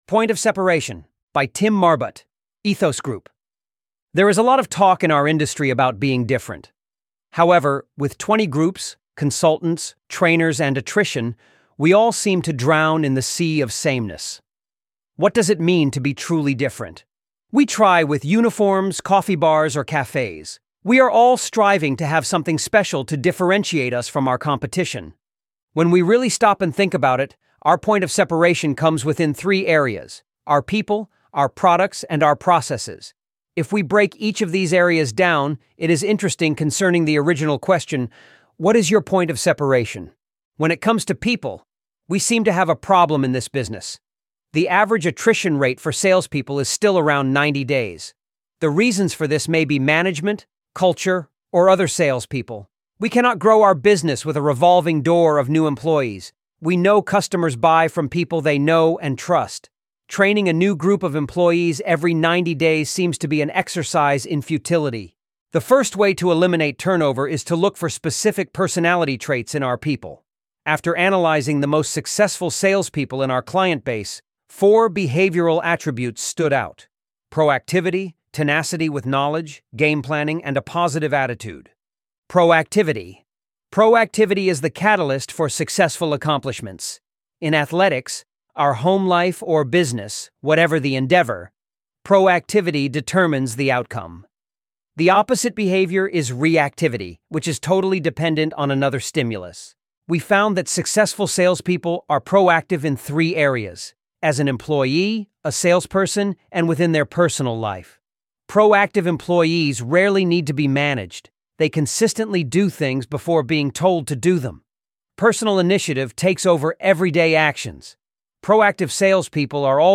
ElevenLabs_Point_of_Separation.mp3